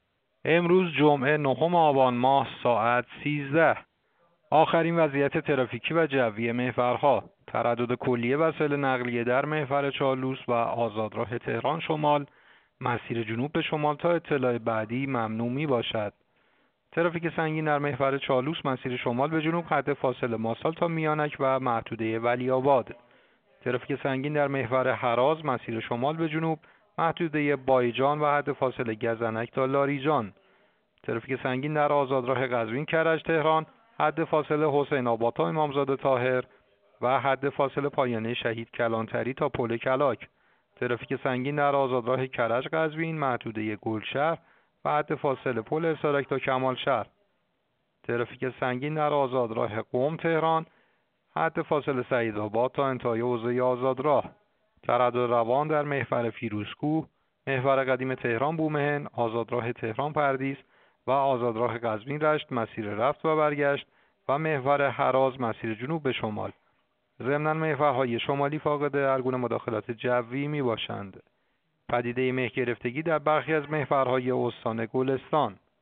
گزارش رادیو اینترنتی از آخرین وضعیت ترافیکی جاده‌ها ساعت ۱۳ نهم آبان؛